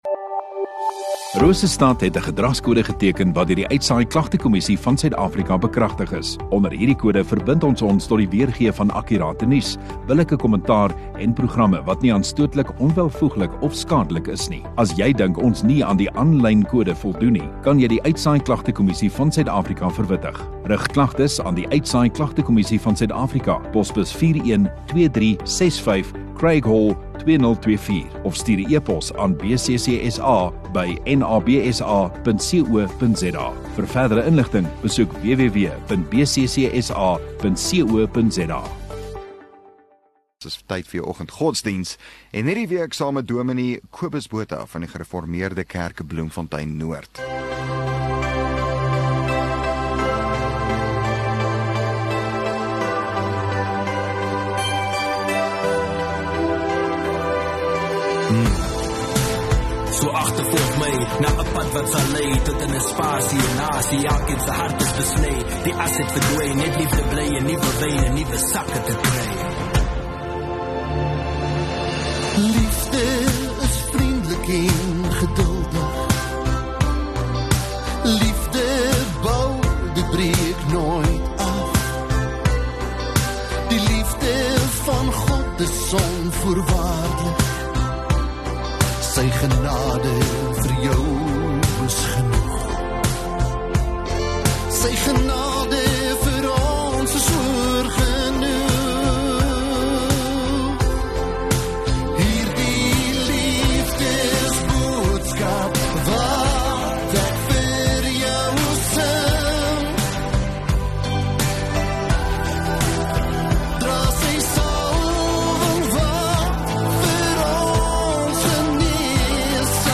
12 May Maandag Oggenddiens